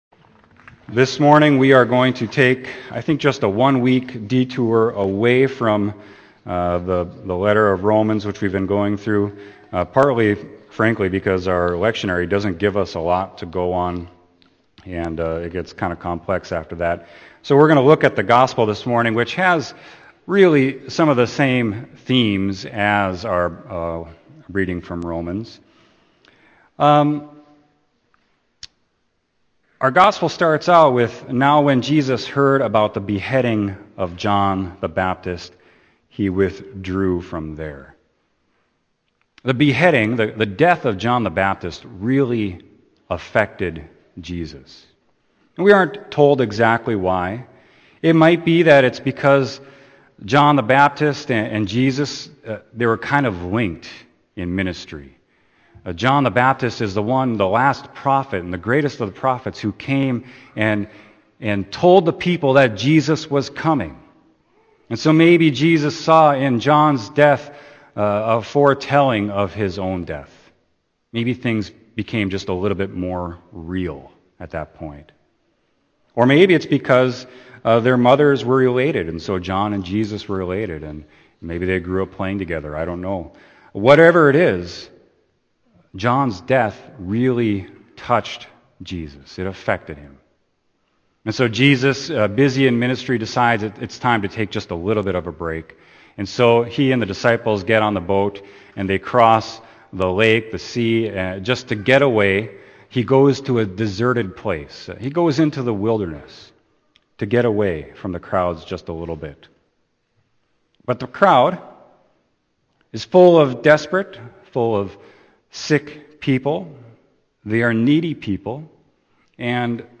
Sermon: Matthew 14.13-21